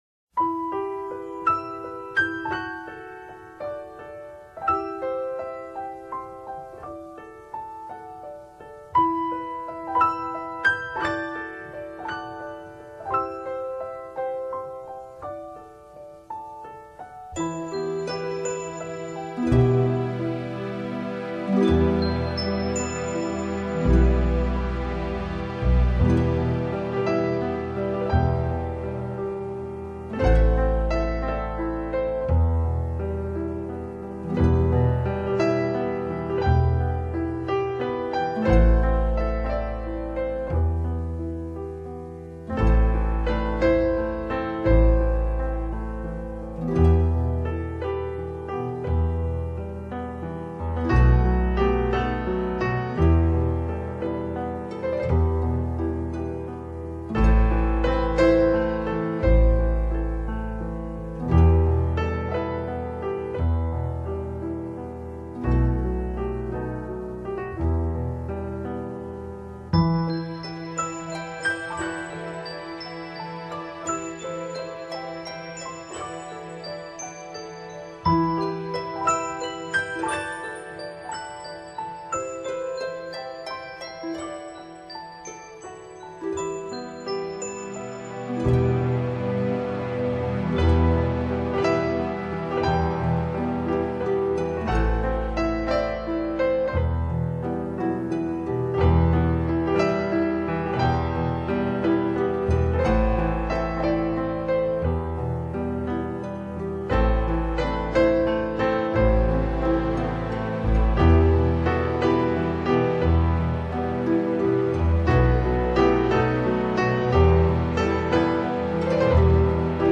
명상음악